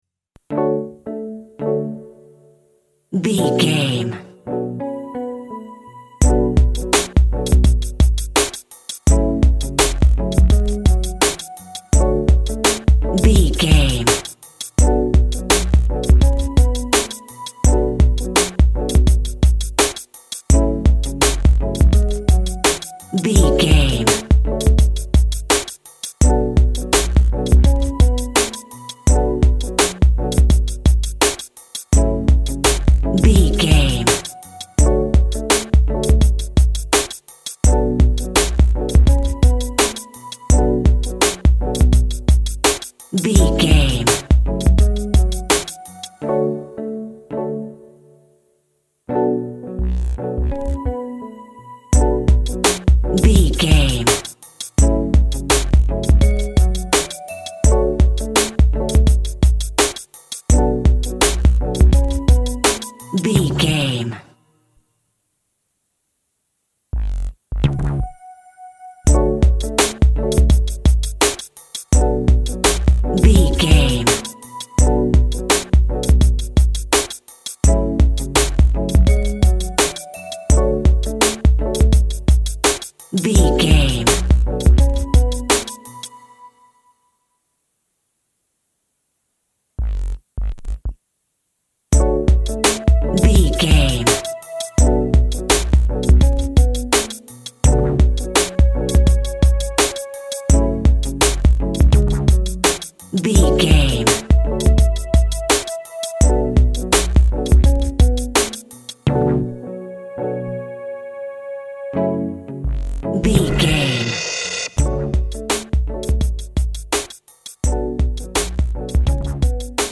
Aeolian/Minor
F#
synth lead
synth bass
hip hop synths
electronics